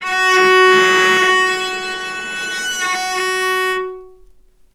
vc_sp-F#4-ff.AIF